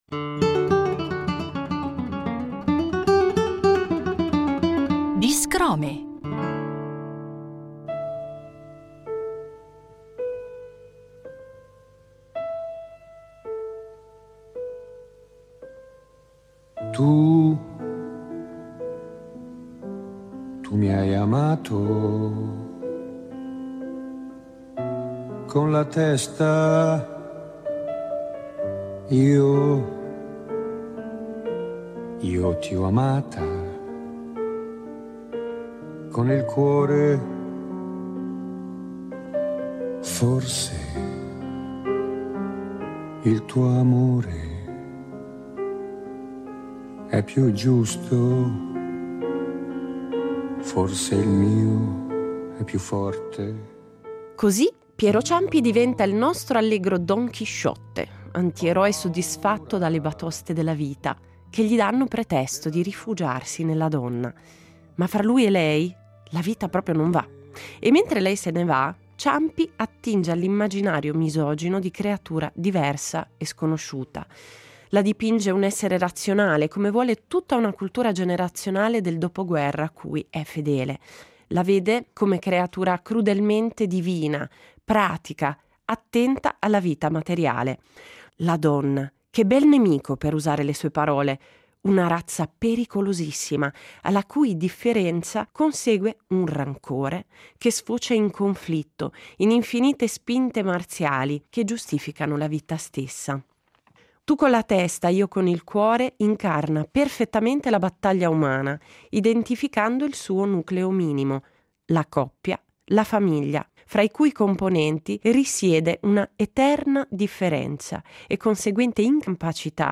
A ciascun episodio è dedicato un brano, punto di partenza per riflessioni musicali, linguistiche e persino antropologiche. L’apertura propone un frammento originale delle storiche incisioni di Piero Ciampi, come gesto di memoria e invocazione d’archivio.
In chiusura, l’ascolto integrale della rilettura musicale tratta dal disco Non siamo tutti eroi, interpretata da un ensemble interamente femminile.